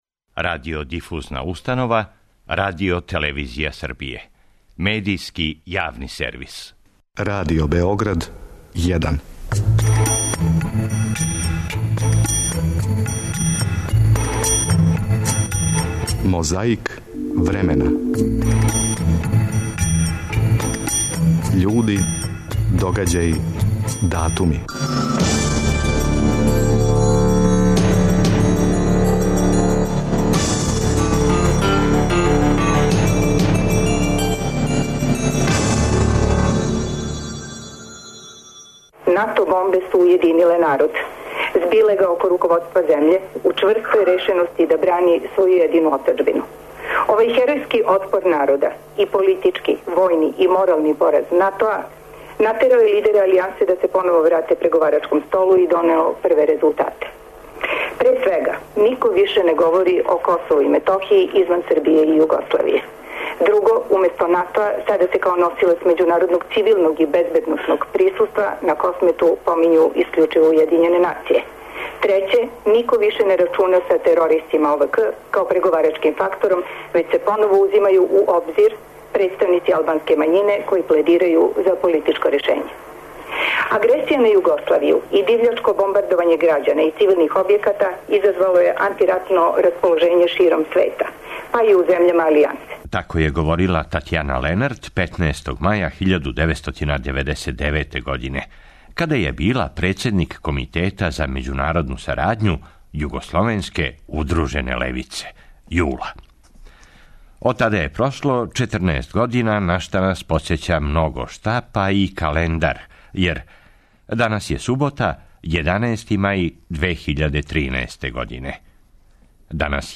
А код нас, такође 18. маја ал' 1987. - место Студеница и речи патријарха Германа.
Подсећа на прошлост (културну, историјску, политичку, спортску и сваку другу) уз помоћ материјала из Тонског архива, Документације и библиотеке Радио Београда.